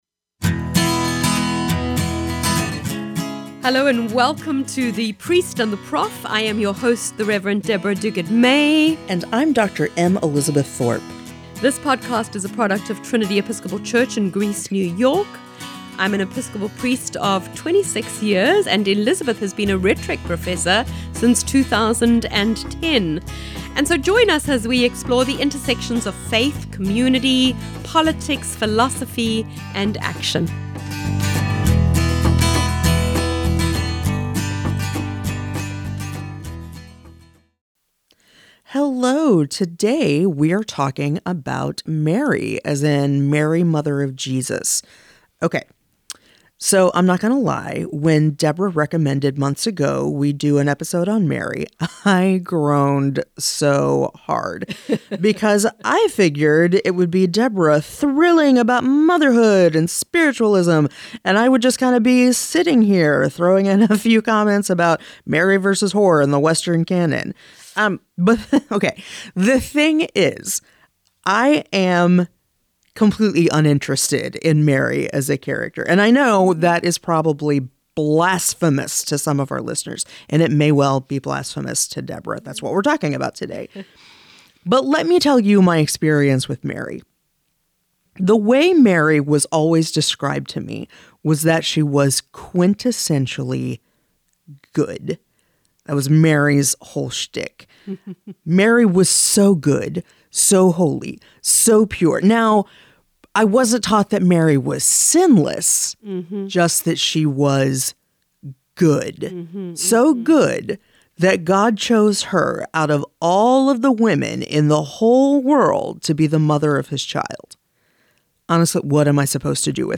The Priest & the Prof is a conversation about faith, culture, and what it means to be a progressive Christian in the 21st century – without all the fire and brimstone.